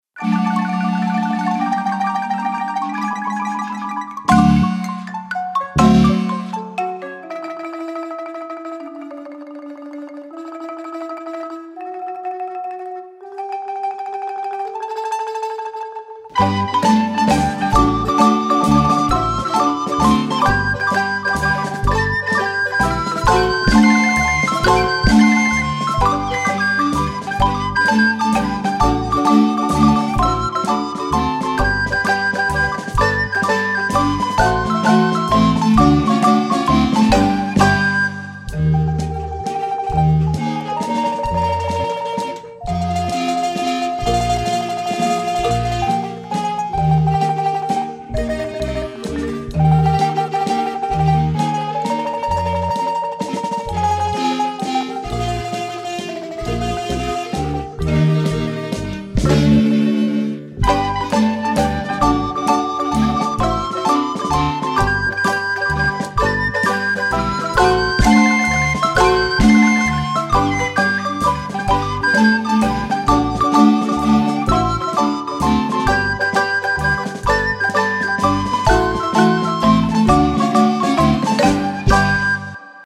flautas